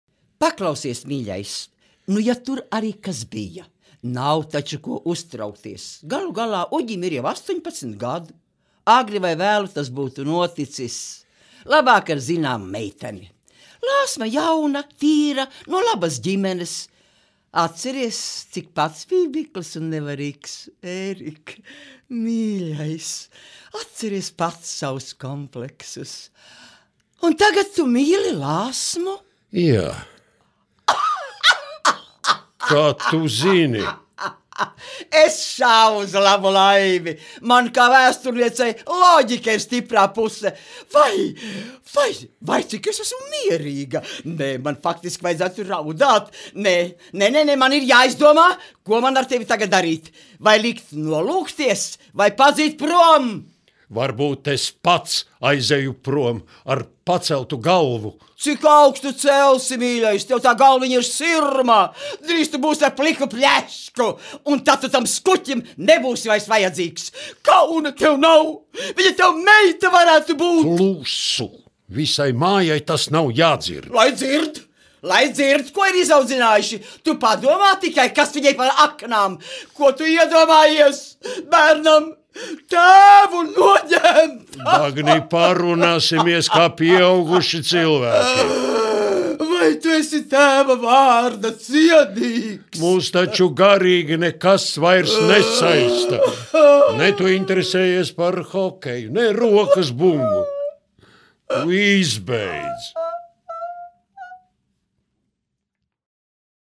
Dialogs latviešu valodā no filmas "Limuzīns Jāņu nakts krāsā"
Dreģe, Olga, 1938- , izpildītājs
Dumpis, Uldis, 1943- , izpildītājs
Latvijas Nacionālās bibliotēkas audio studijas ieraksti (Kolekcija)